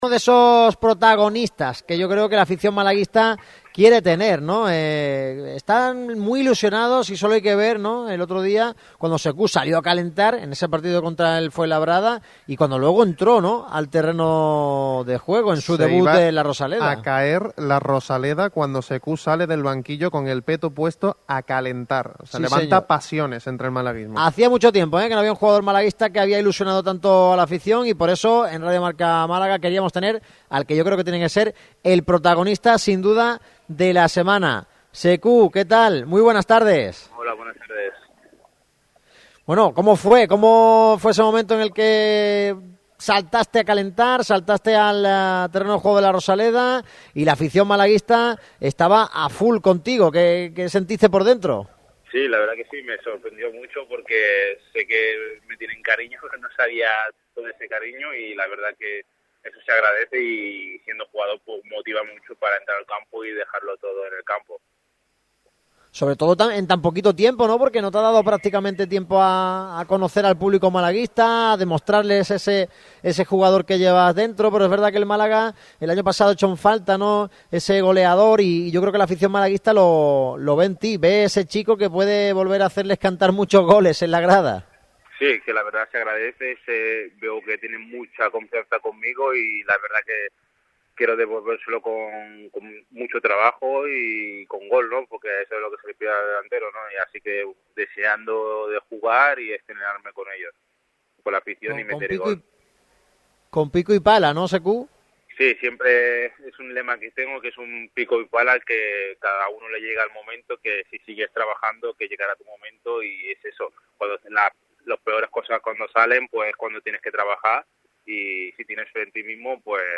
El flamante nuevo delantero del Málaga CF, Sekou Gassama, ha pasado por el micrófono rojo de Radio MARCA Málaga para analizar la situación del equipo y la suya propia.